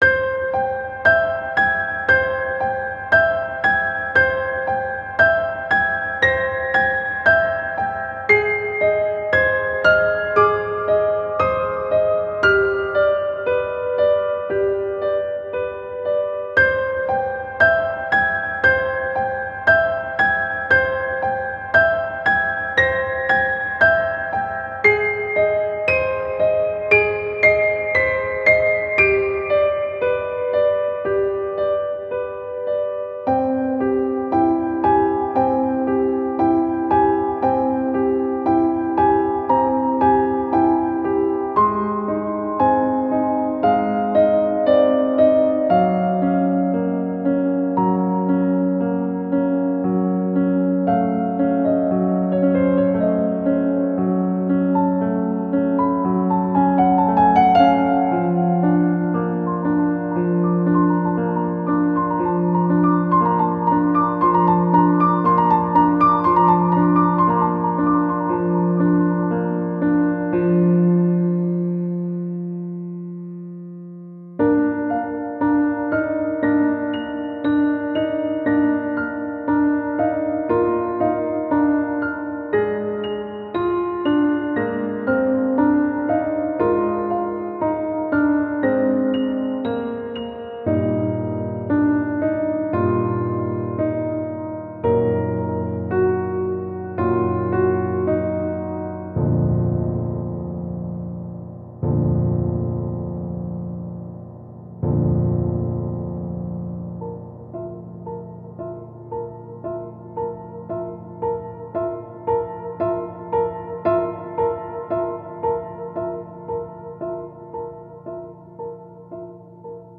-oggをループ化-   緊張感 ホラー 2:05 mp3